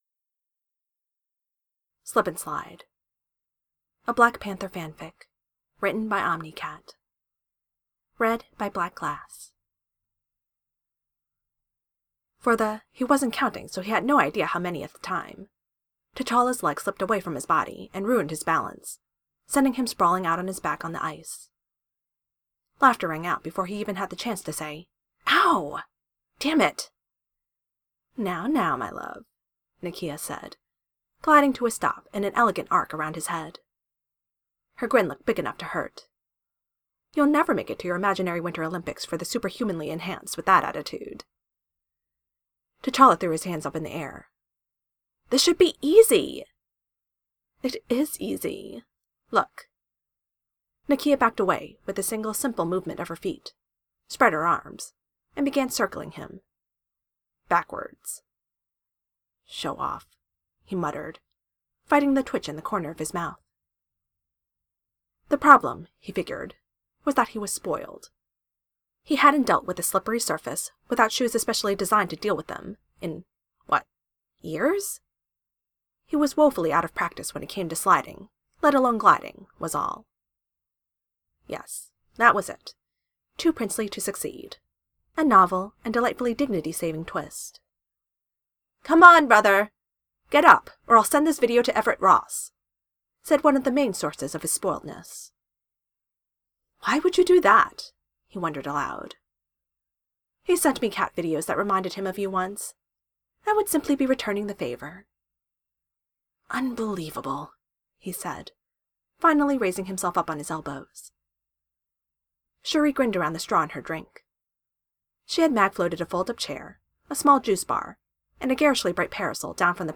Fluff Siblings Established Relationship Ice Skating Podfic Audio Format: MP3 Audio Format: Streaming Podfic Length: 0-10 Minutes Trick or Treat: Treat